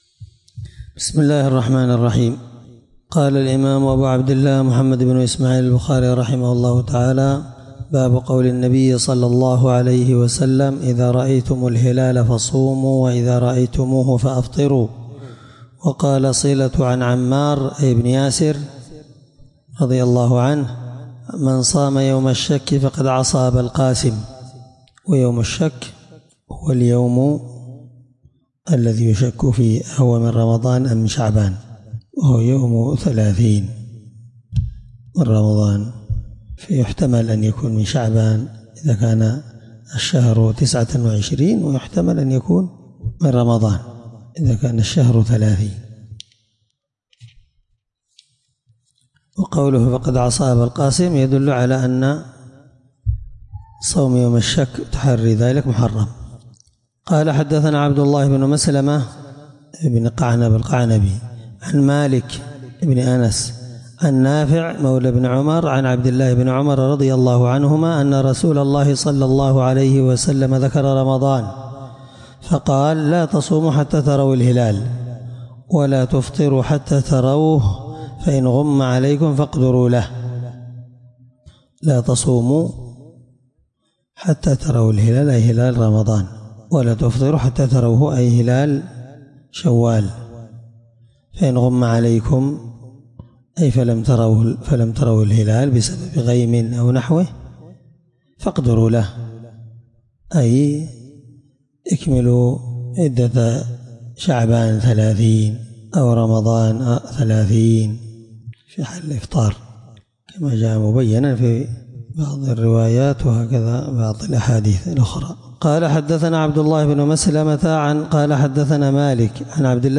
الدرس 11من شرح كتاب الصوم حديث رقم(1906-1911 )من صحيح البخاري